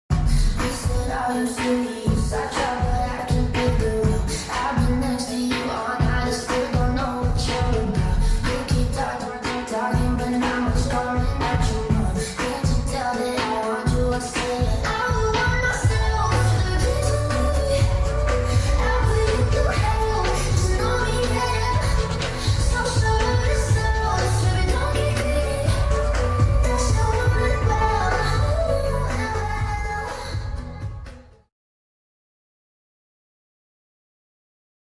🎧 16D - WEAR HEADPHONES 🎧